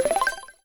success-word.wav